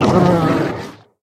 Minecraft Version Minecraft Version snapshot Latest Release | Latest Snapshot snapshot / assets / minecraft / sounds / mob / polarbear / death2.ogg Compare With Compare With Latest Release | Latest Snapshot